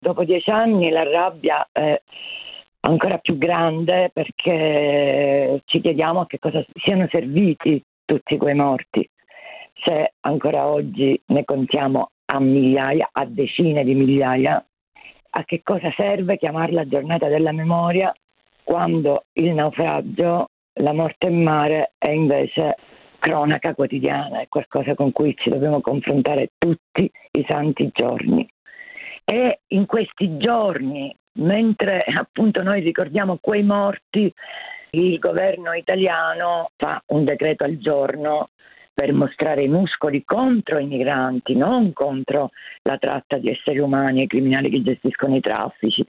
Su questi 10 anni e sugli ultimi interventi del governo in tema di immigrazione sentiamo Giusi Nicolini, che era sindaca di Lampedusa quando avvenne il naufragio: